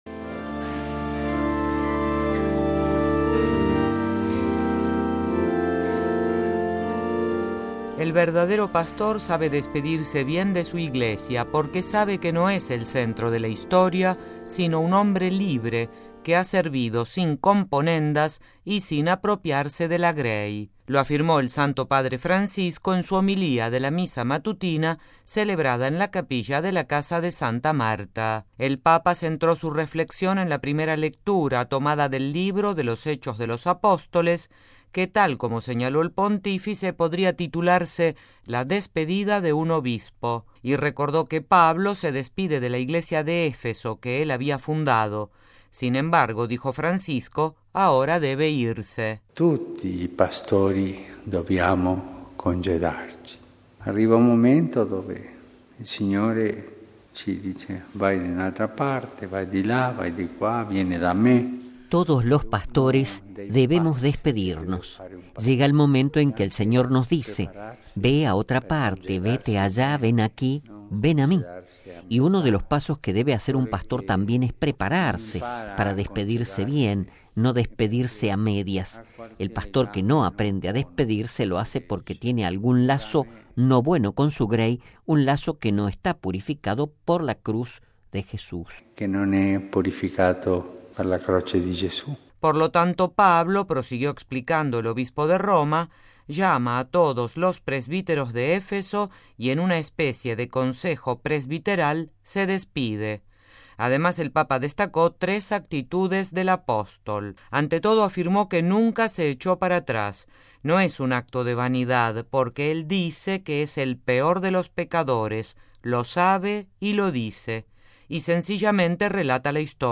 Lo afirmó el Santo Padre en su homilía de la Misa matutina celebrada en la capilla de la Casa de Santa Marta.